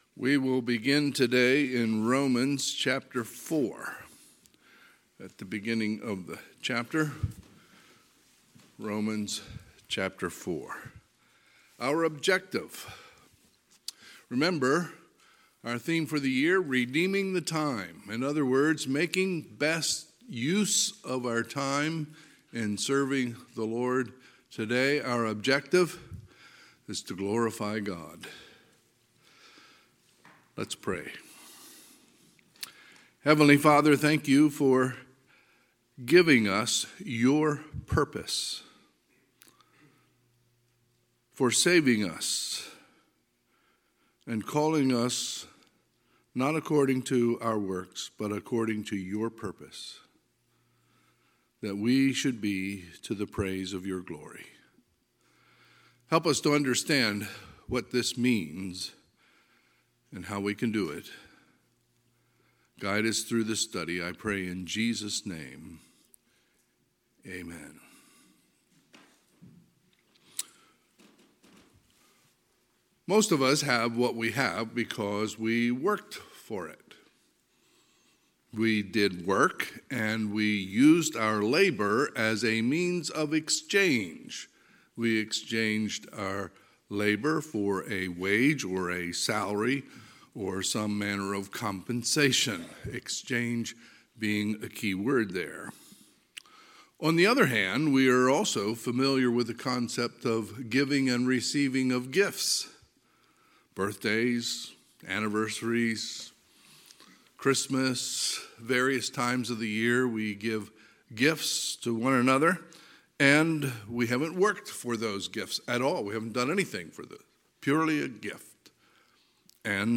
Sunday, April 24, 2022 – Sunday AM
Sermons